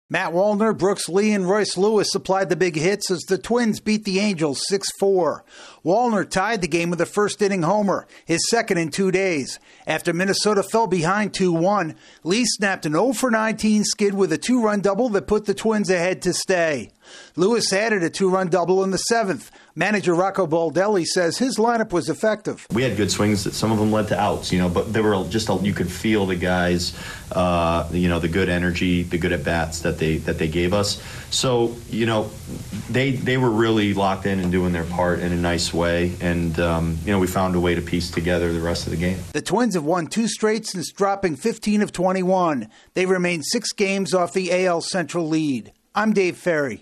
The Twins strengthen their hold on a wild-card berth. AP correspondent